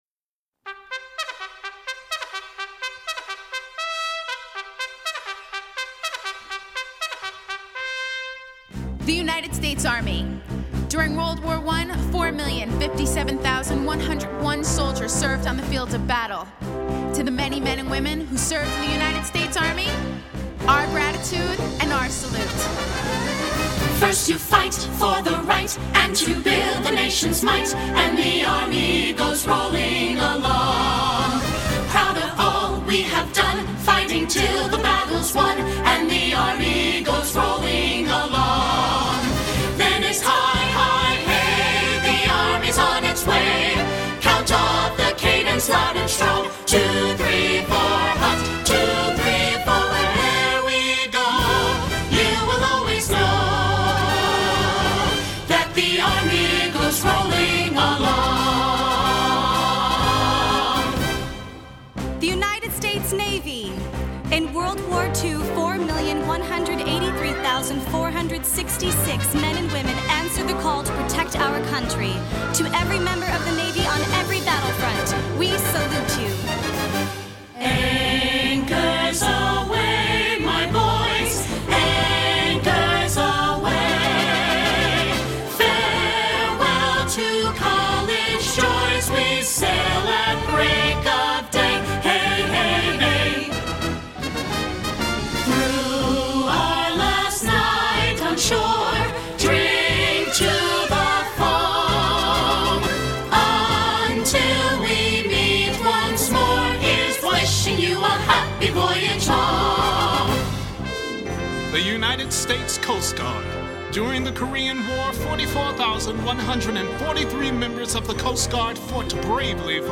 Favorite patriotic songs presented in a fun way!